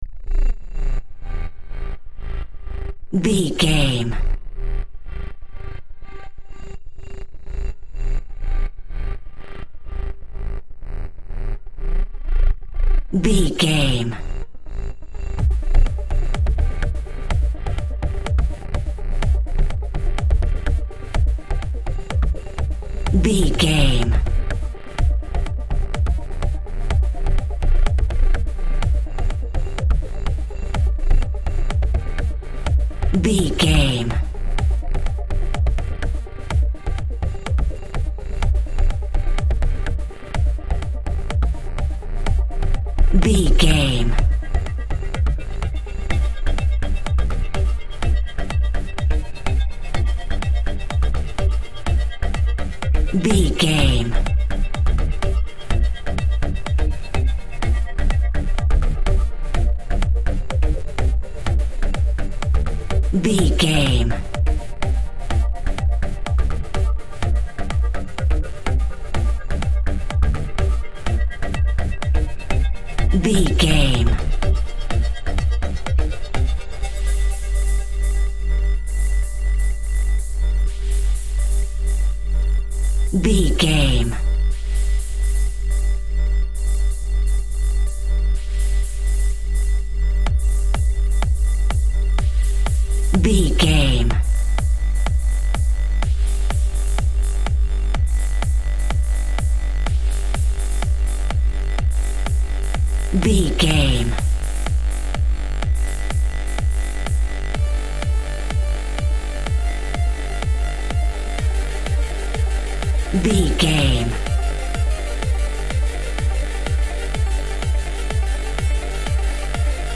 Epic / Action
Fast paced
Aeolian/Minor
Fast
dark
futuristic
groovy
aggressive
synthesiser
drum machine
house
techno
trance
synth leads
synth bass
upbeat